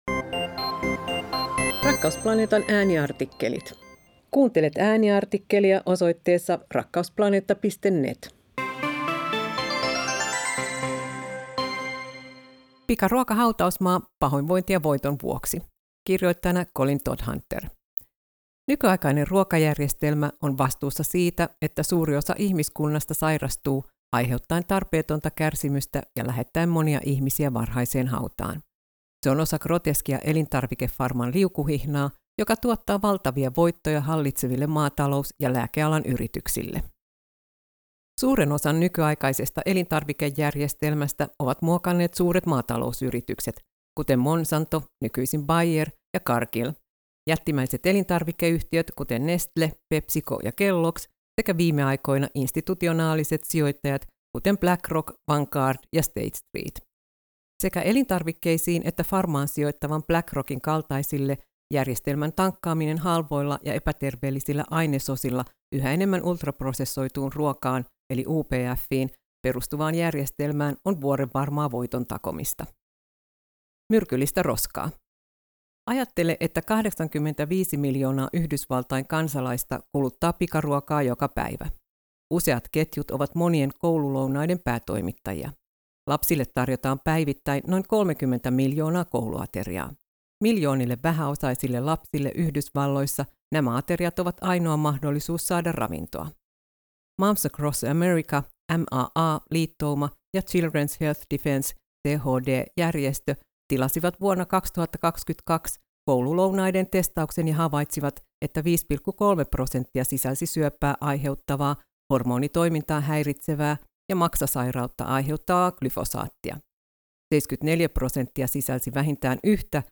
Pikaruoka-hautausmaa-Aaniartikkeli-AM.mp3